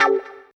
137 GTR 9 -R.wav